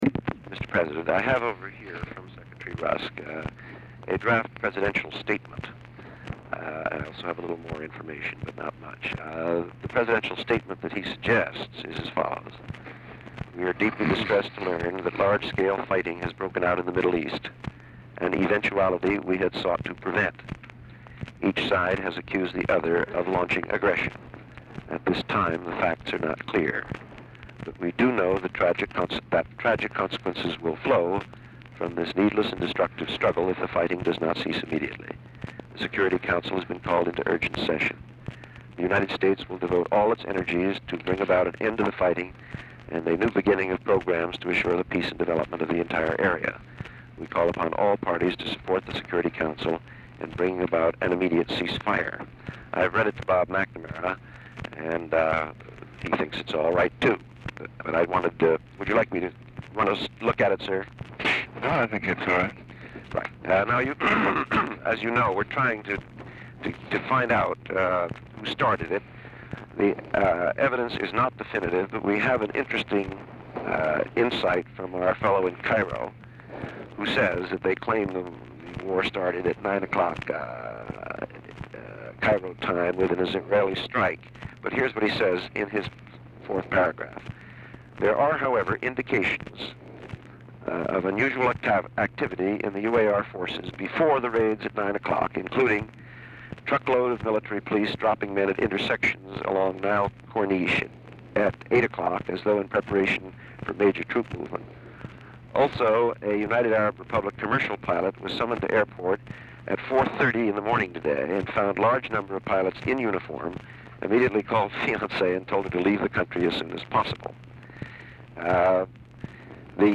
Mansion, White House, Washington, DC
Telephone conversation
Dictation belt